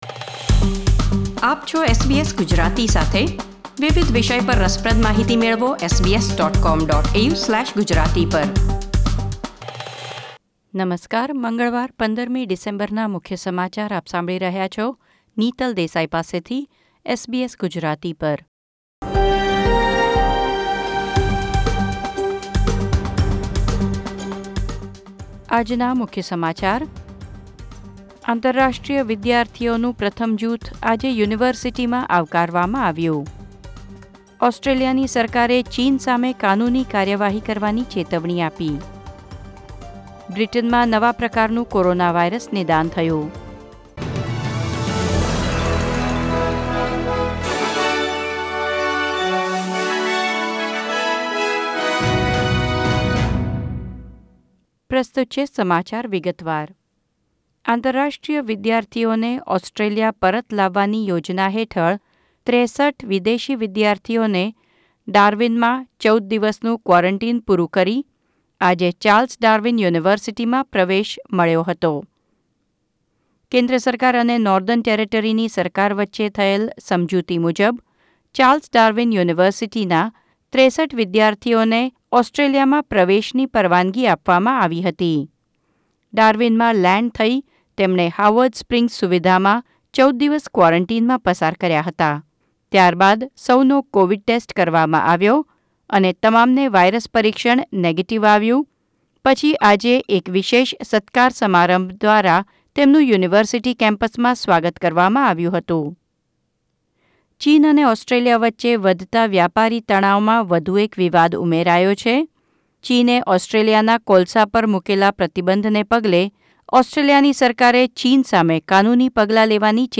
SBS Gujarati News Bulletin 15 December 2020